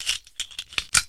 - Attach and detach weapon attachments has a sounds now.
action_attach_0.ogg